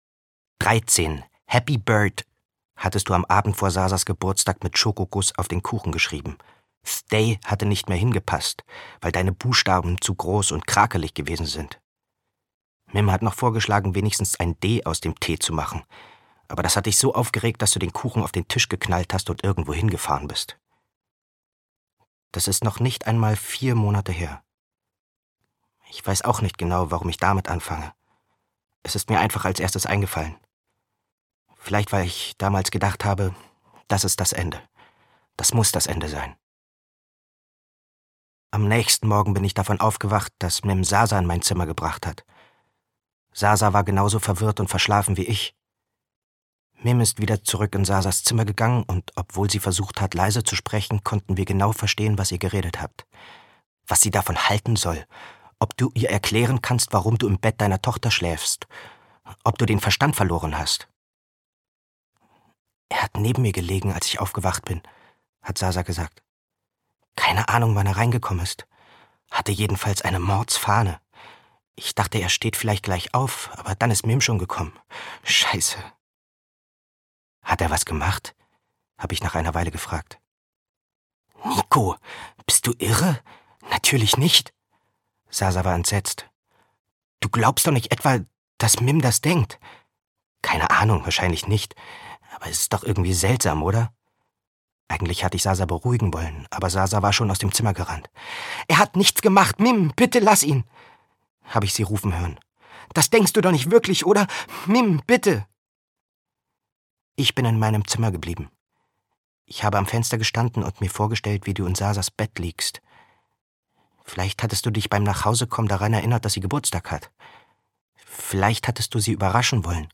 Katertag. Oder: Was sagt der Knopf bei Nacht? - Regina Dürig - Hörbuch